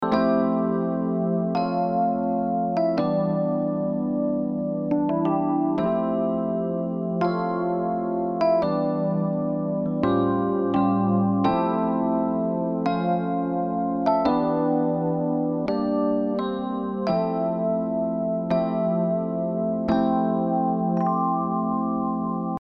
アタック最速
かすかな変化なのでわかりにくいかと思いますがニュアンスが変わったのが分かるかと思います。